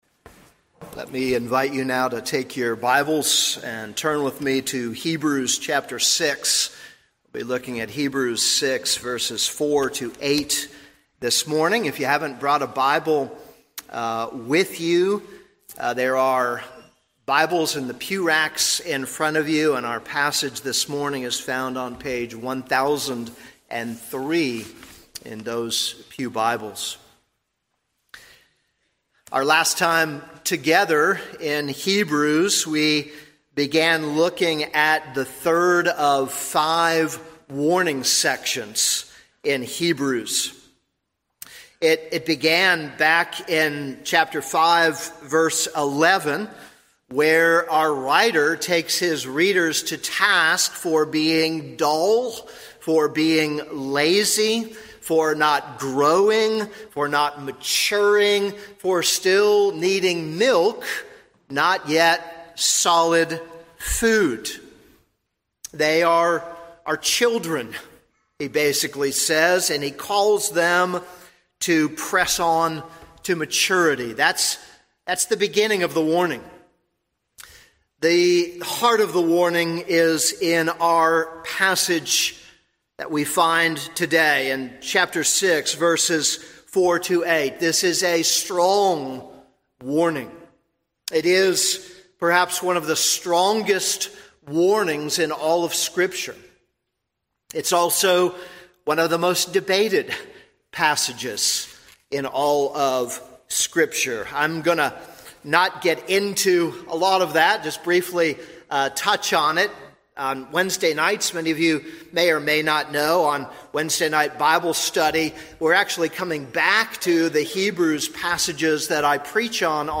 This is a sermon on Hebrews 6:4-8.